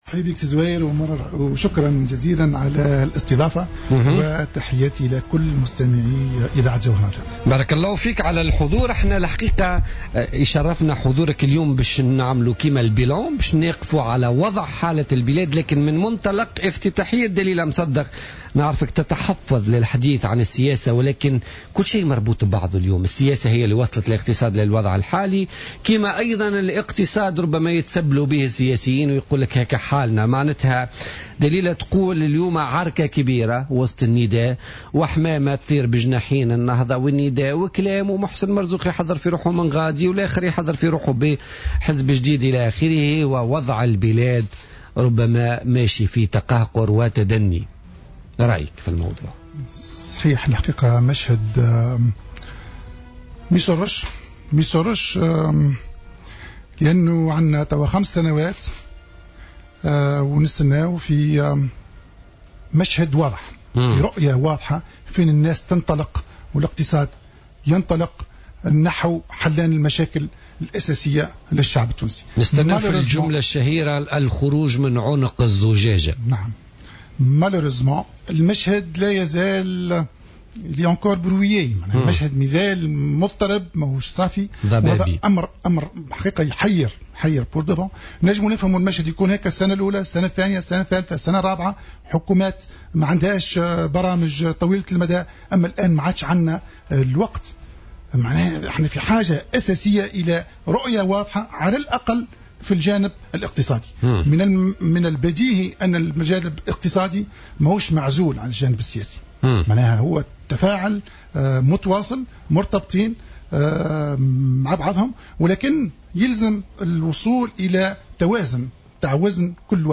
أكد وزير السياحة الأسبق جمال قمرة ضيف بوليتيكا اليوم الثلاثاء 12 جانفي 2016 أن المشهد السياسي الحالي لا يسر لأن 5 سنوات مرت دون أن يطرأ جديد ودون أن يتم التوصل لحلول جذرية ناجعة للإقتصاد المتدهور .